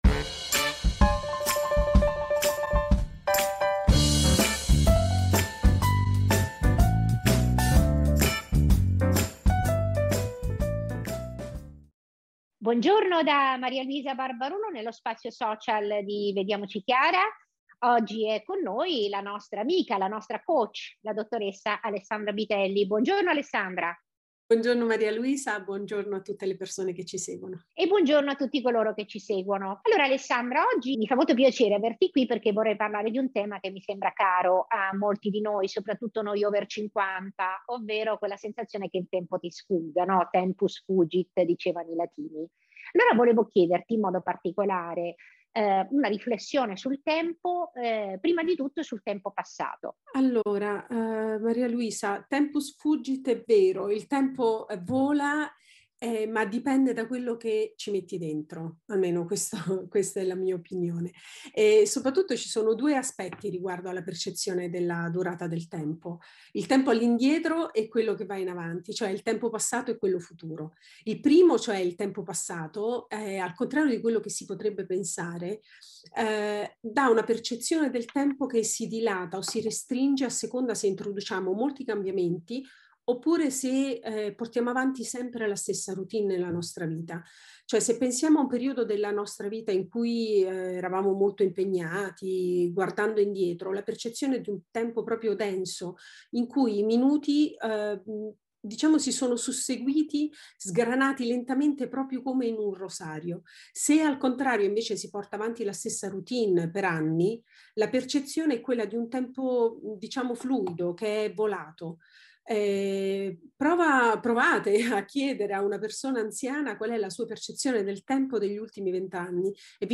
Mini-intervista alla nostra coach